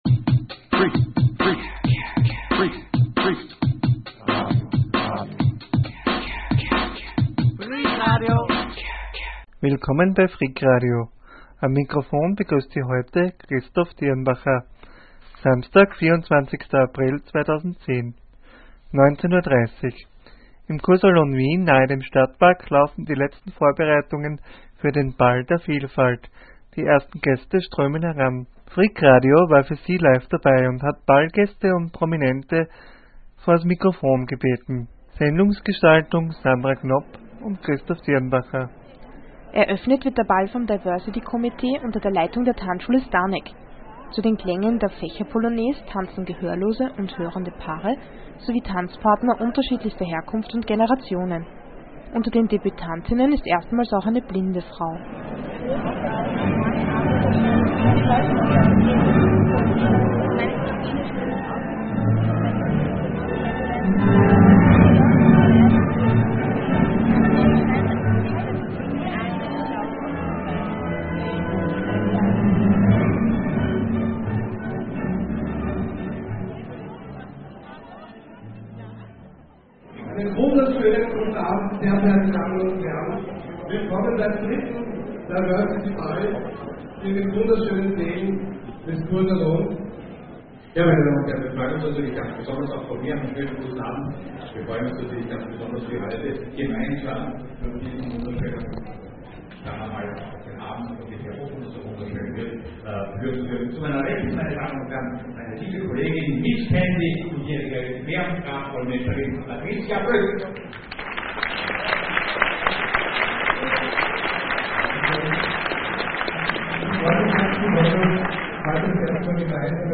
Freak-Radio ist live dabei.
In der 30-minütige Reportage erfahren Sie alle Höhepunkte.
Mai 2010 Diversity Ball von Redaktion Am 24. April findet im Kursalon Wien zum dritten mal die Ballnacht der Vielfalt statt.